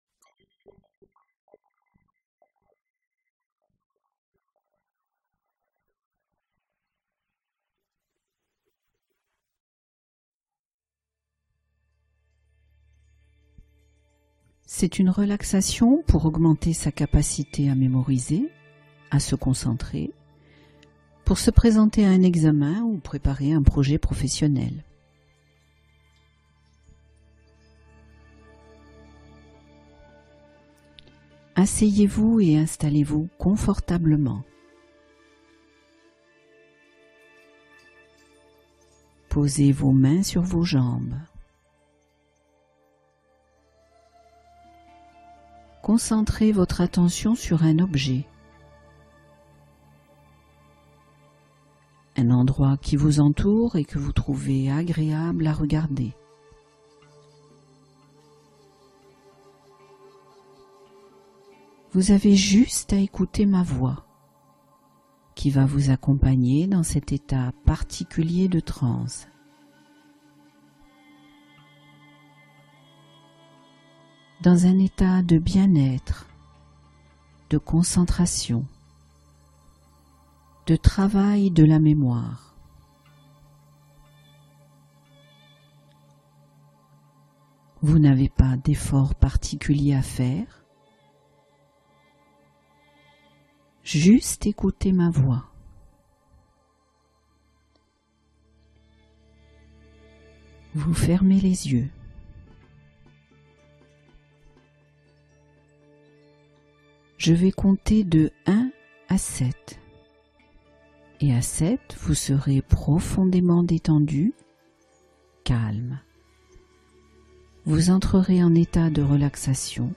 Force Intérieure : Hypnose pour cultiver sa confiance et son assurance